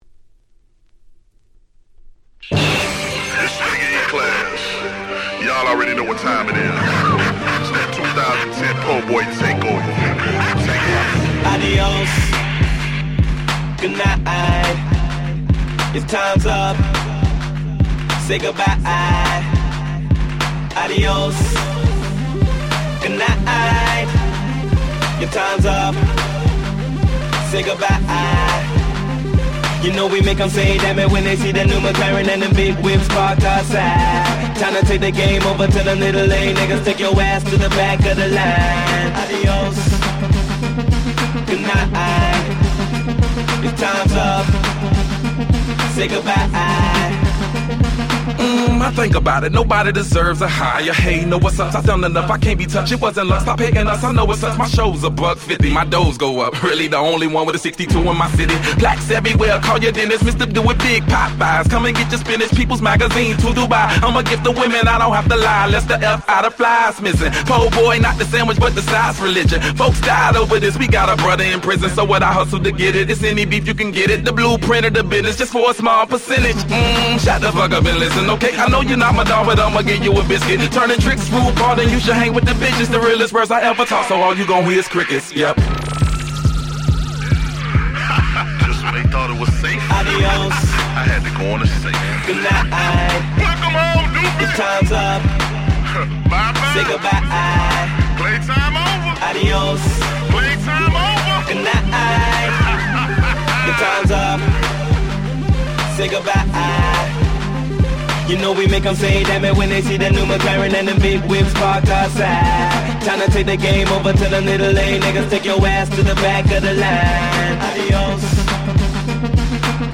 フローライダ デヴィットゲッタ EDM アゲアゲ パリピ 10's